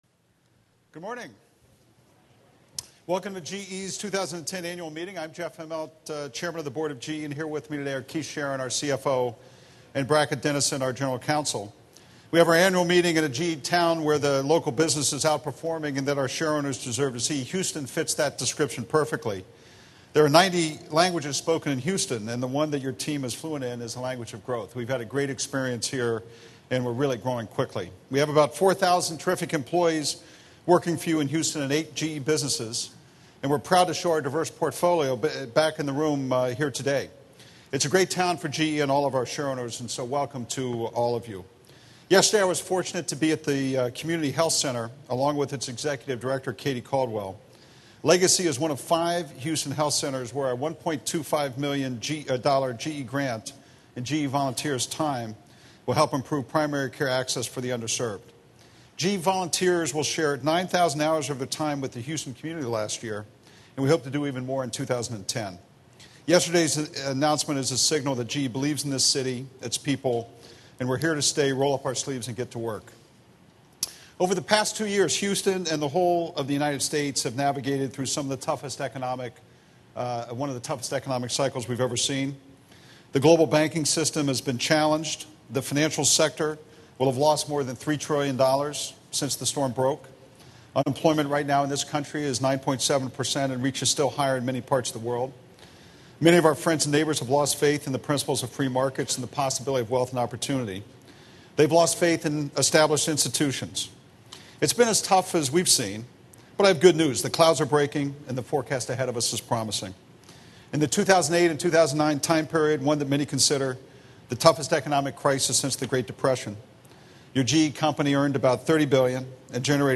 GE convened its 2010 Annual Meeting of Shareowners in Houston, Texas.